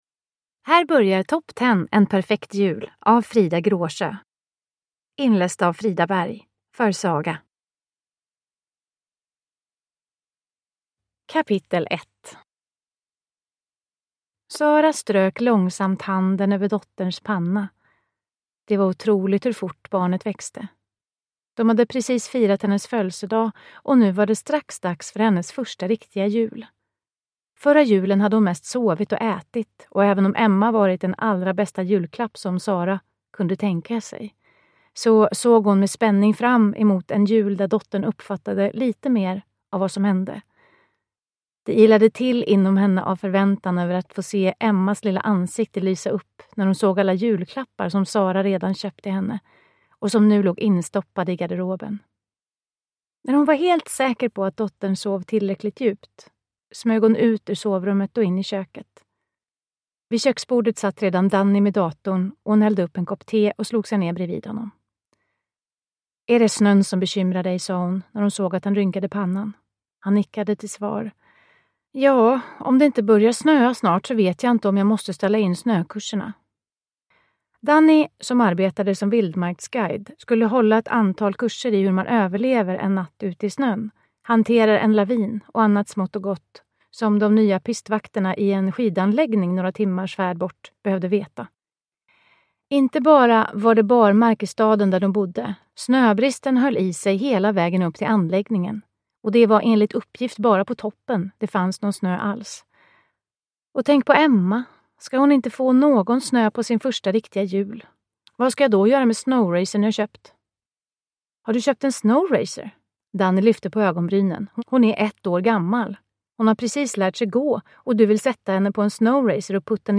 Top ten - en perfekt jul – Ljudbok – Laddas ner